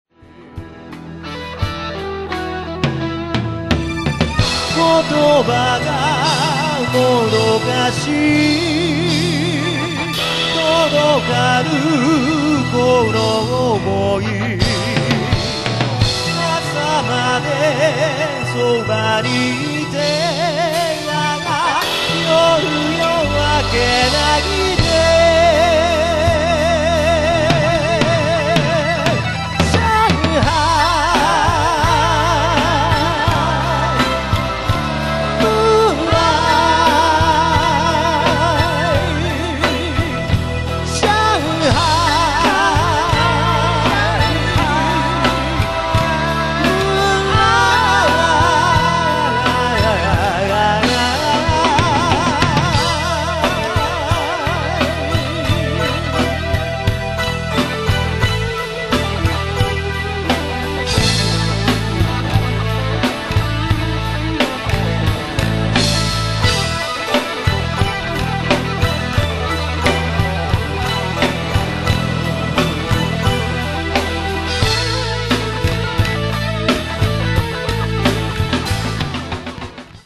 若いからできるライヴ
vocal
guitar
keybords,chorus
bass,chorus
drums